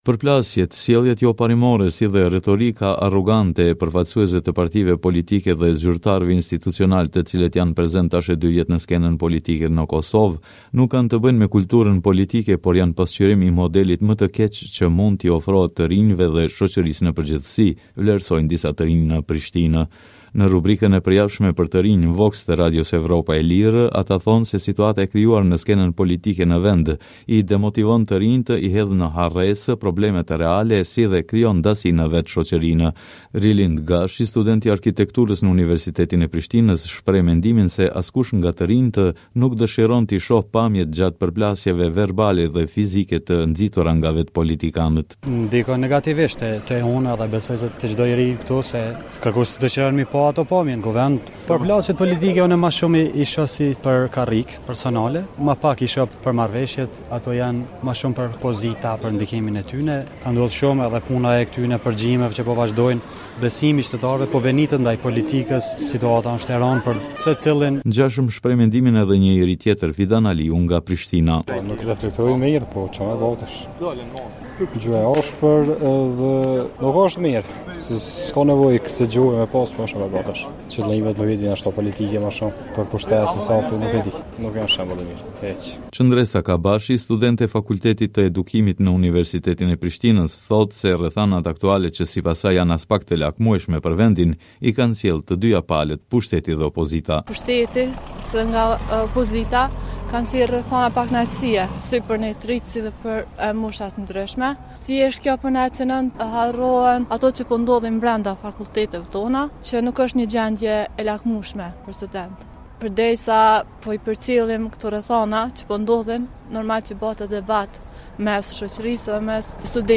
Vox me të rinjë